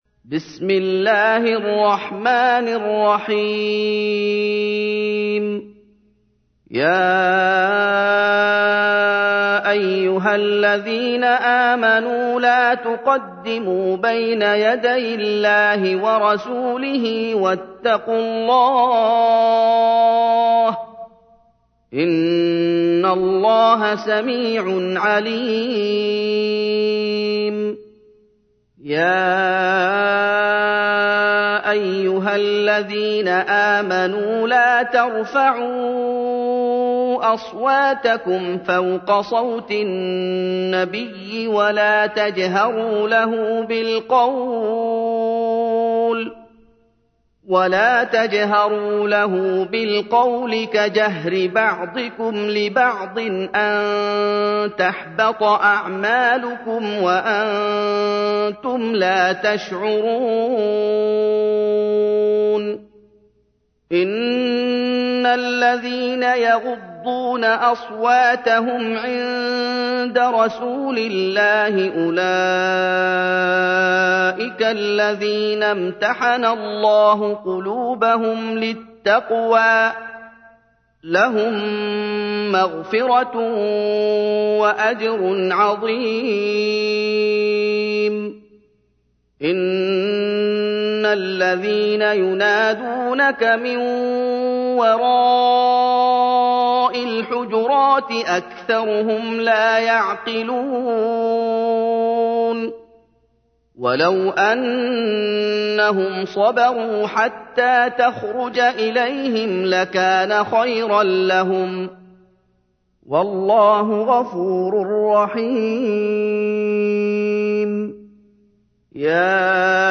تحميل : 49. سورة الحجرات / القارئ محمد أيوب / القرآن الكريم / موقع يا حسين